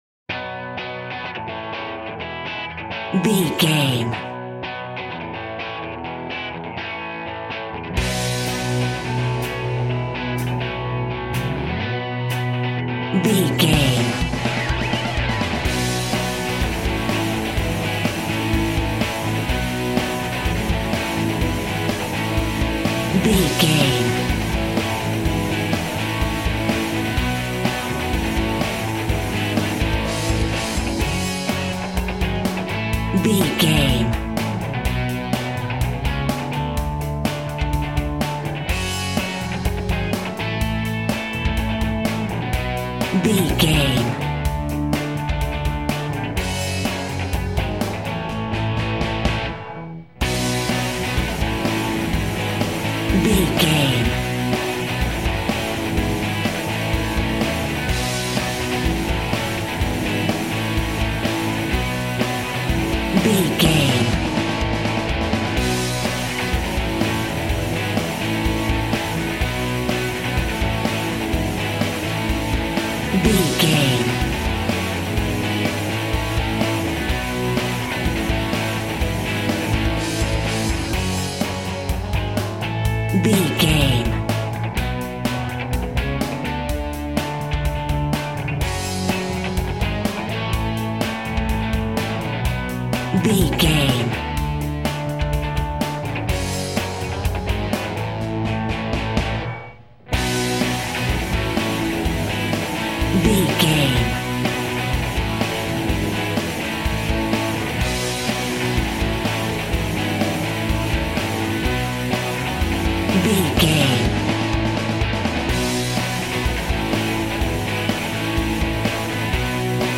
Summer Rock Music.
Ionian/Major
energetic
heavy
aggressive
electric guitar
bass guitar
drums
distortion
hard rock
Instrumental rock